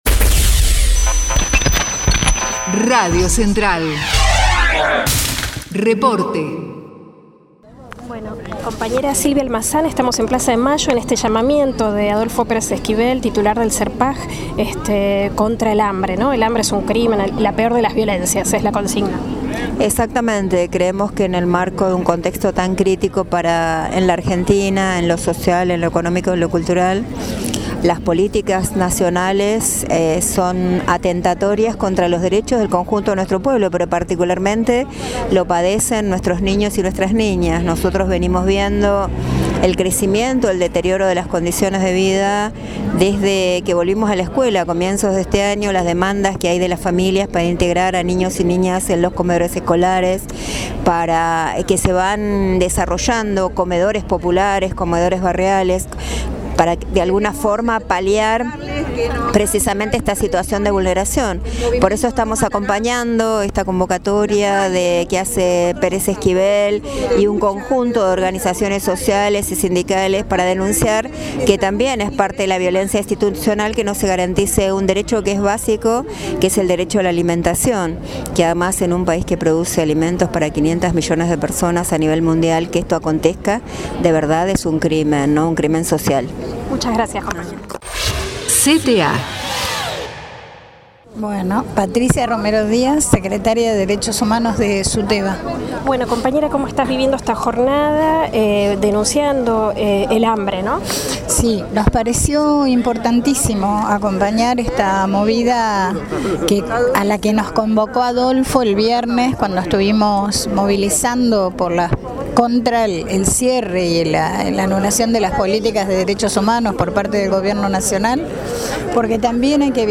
LA PEOR VIOLENCIA ES EL HAMBRE: Testimonios CTA-T